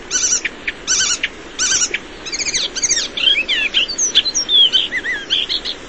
Canapino maggiore
Hippolais icterina
Richiami ‘tec tec tec’ o ‘uit’. Canto lungo e vario, gorgheggiante e fortemente imitativo. Occasionalmente ricorda il Tordo sassello, ma più spesso è simile alla Cannaiola verdognola; differisce da entrambi per il ricorrente ‘dideroid dideroid’.
Canapino_Maggiore_Hippolais_icterina.mp3